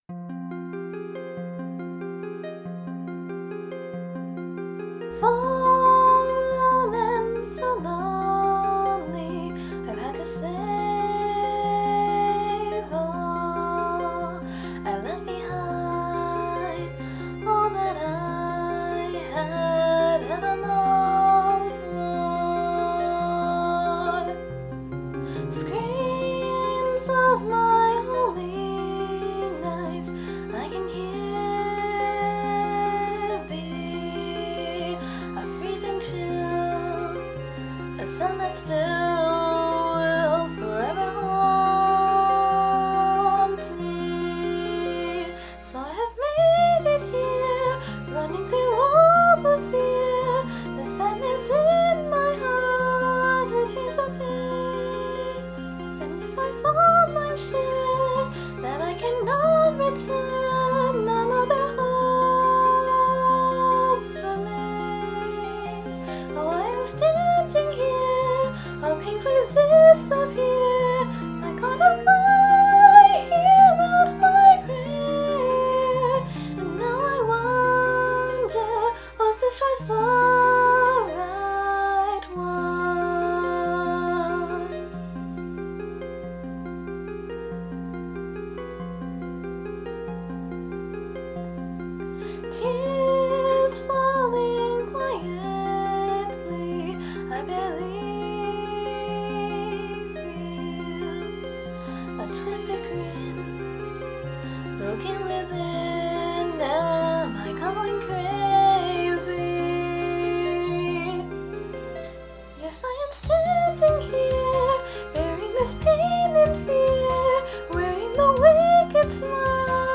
We finally finished singing covers for those songs!
Staff Rendition 3: